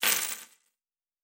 Coins 15.wav